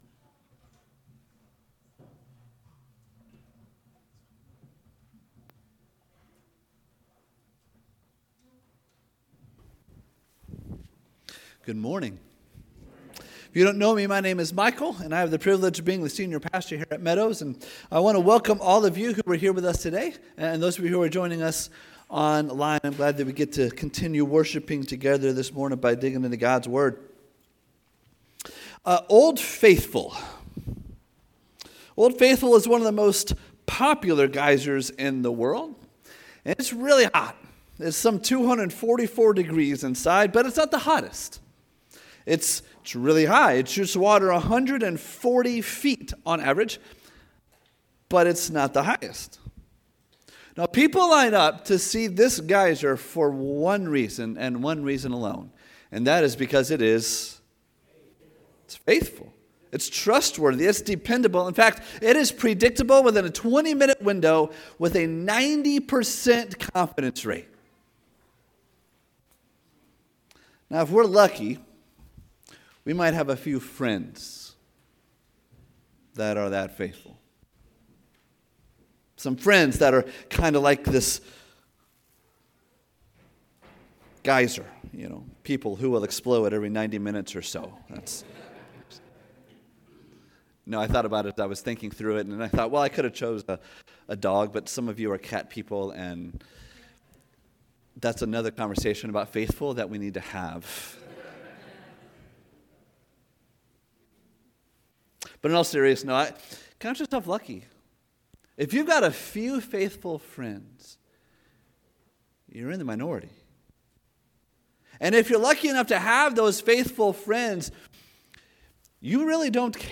Sermons | Meadows Christian Fellowship